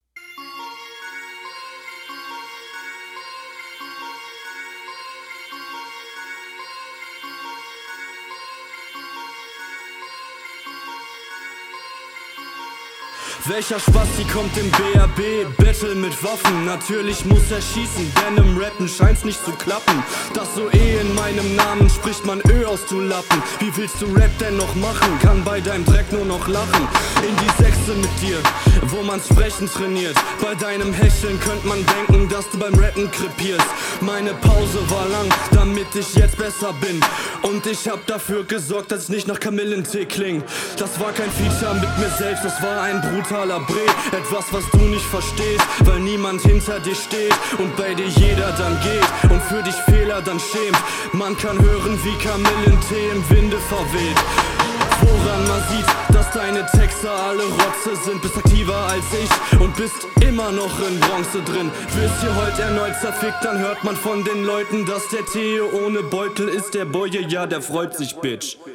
Guter Stimmeinsatz, das die geringe BPM liegt dir ein bisschen weniger aber passt immer noch.
Stimme gefällt und die haste auch besser unter Kontrolle als dein Gegner.